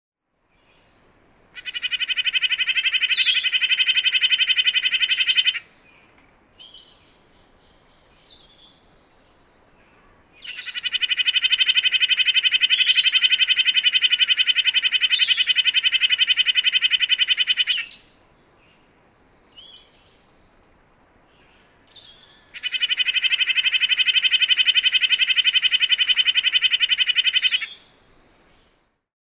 Подборка включает разные варианты голосов, записанных в естественной среде обитания.
Канадский поползень исполняет мелодию